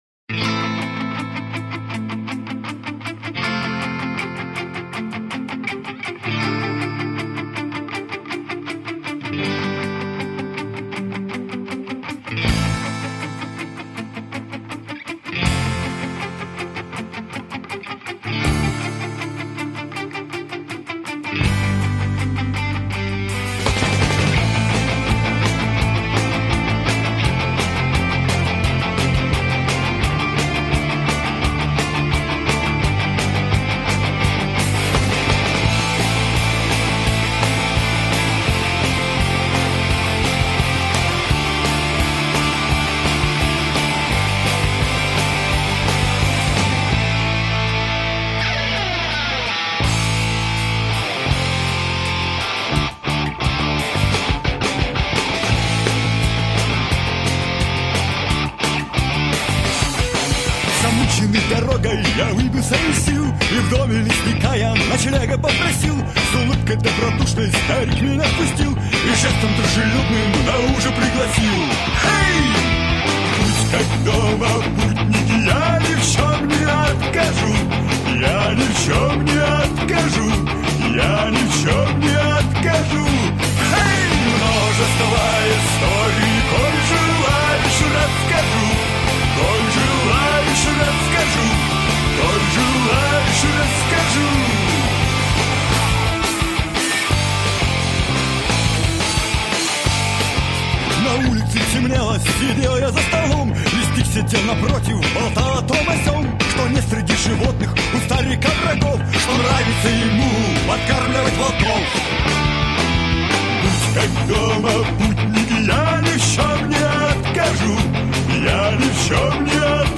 Веселенькая песня